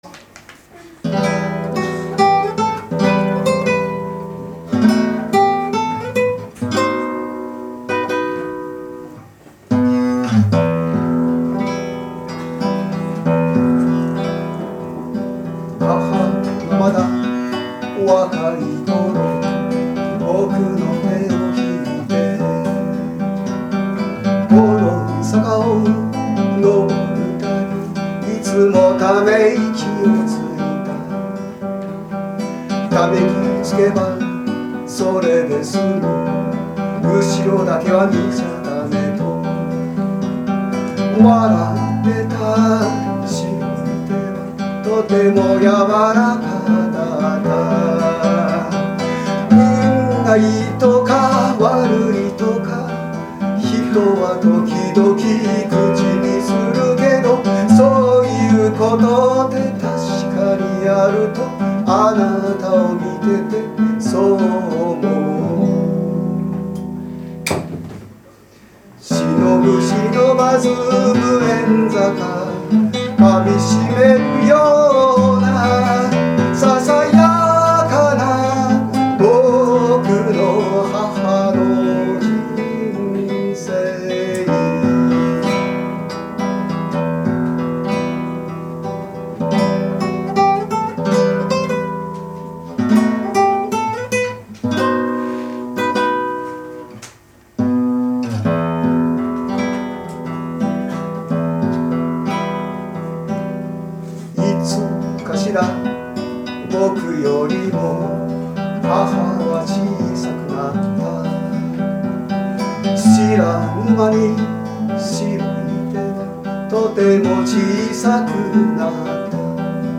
弾き語り